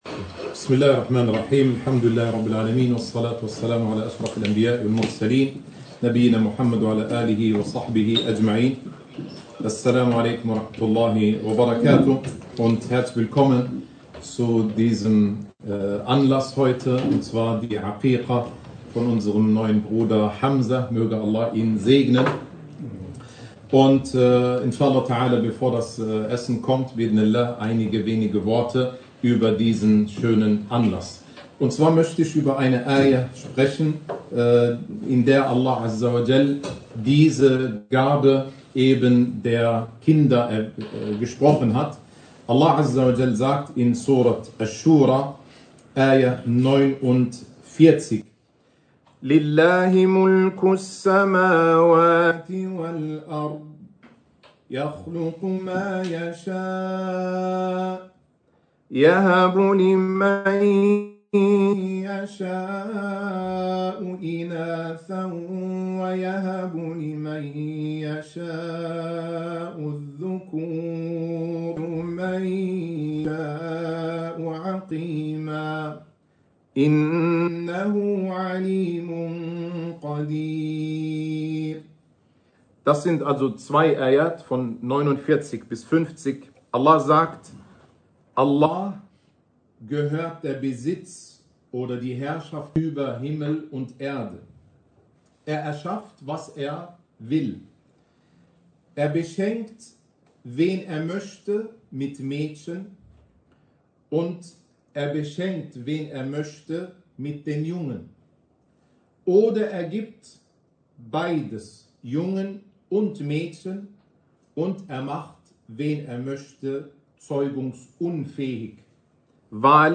Aqiqa-Vortrag - Surah Ash-Shura Vers 49-50.mp3